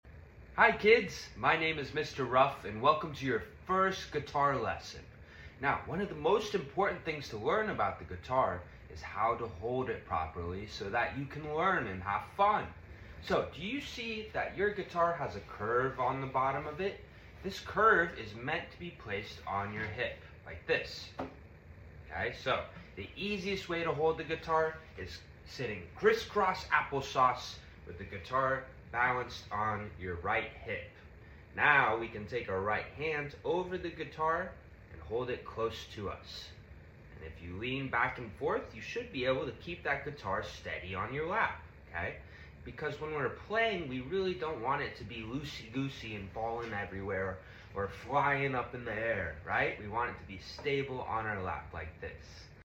Here’s your First guitar lesson sound effects free download